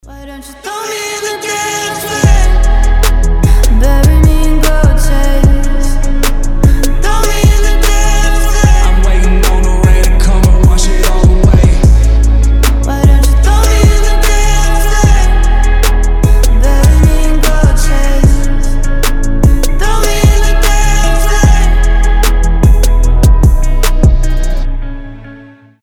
• Качество: 320, Stereo
Хип-хоп
мелодичные
дуэт
красивый женский голос